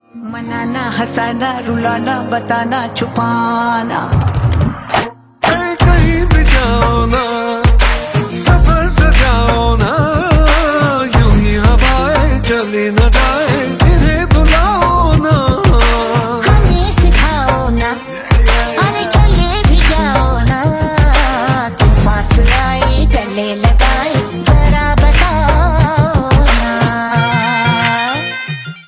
creating a heart touching melody.